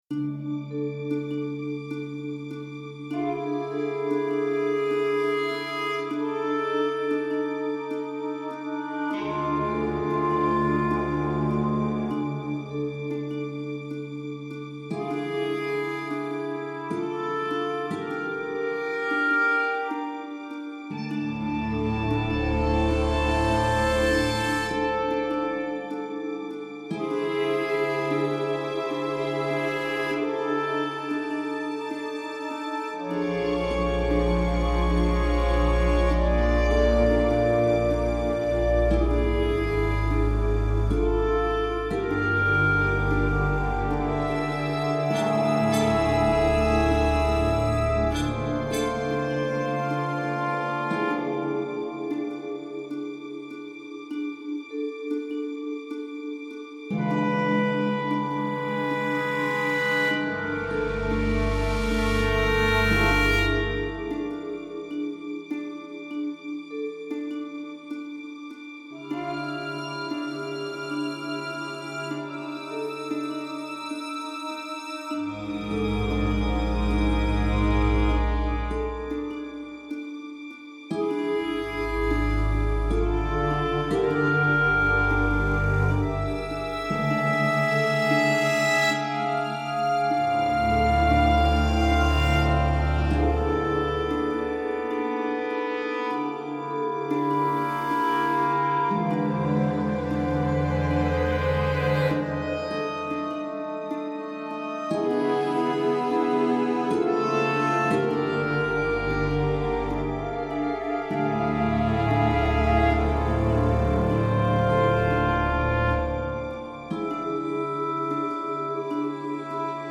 SUSPENS/TENSION